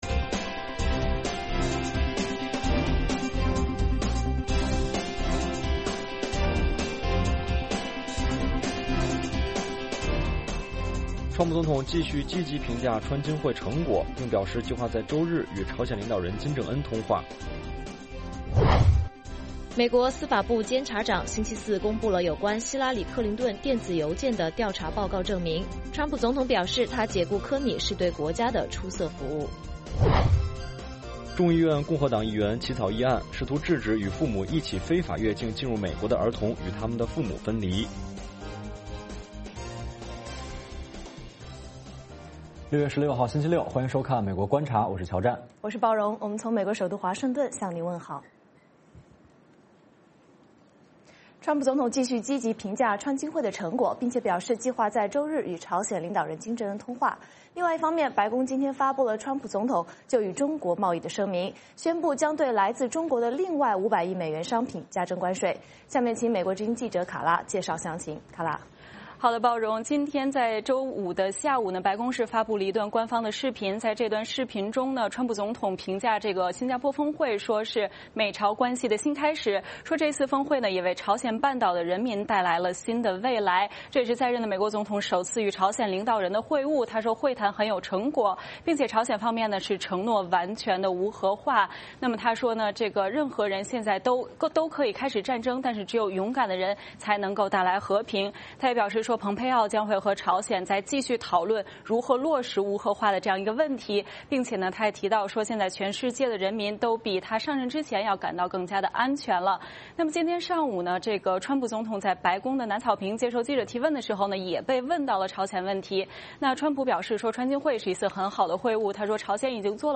美国之音中文广播于北京时间晚上8－9点重播《VOA卫视》节目(电视、广播同步播出)。
“VOA卫视 美国观察”掌握美国最重要的消息，深入解读美国选举，政治，经济，外交，人文，美中关系等全方位话题。节目邀请重量级嘉宾参与讨论。